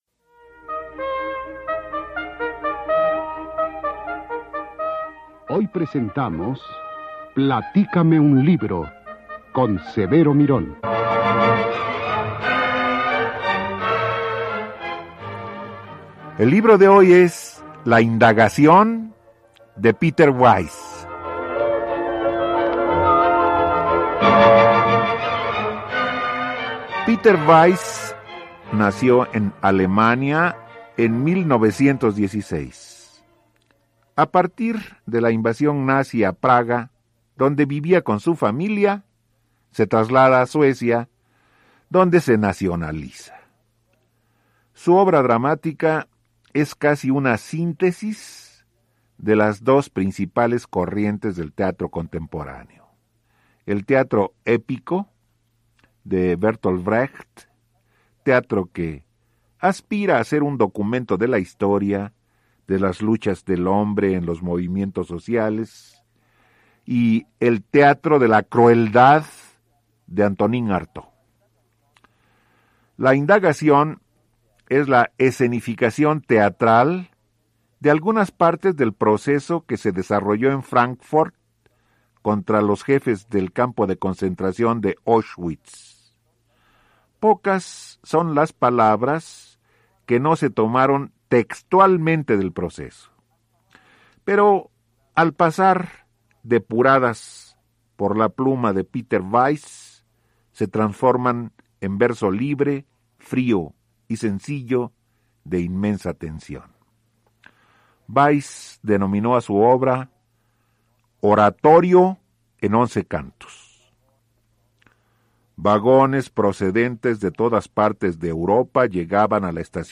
obra de Peter Weiss narrada